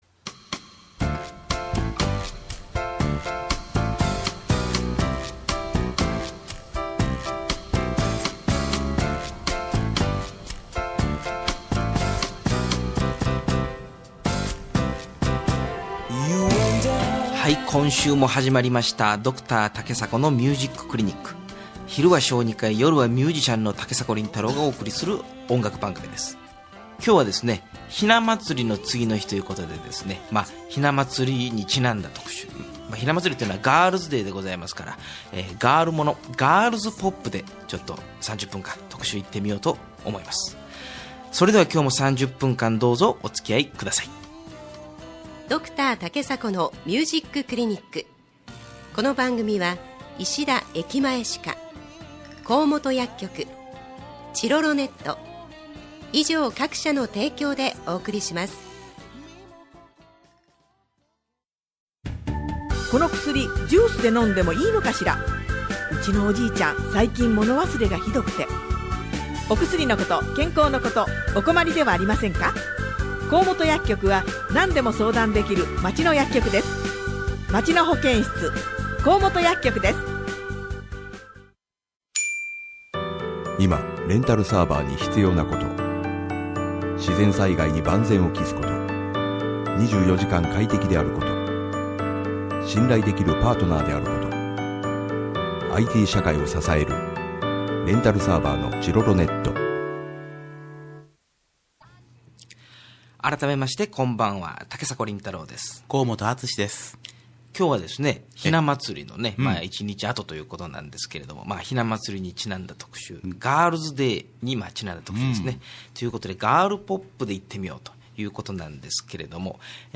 第13回放送録音をアップしました。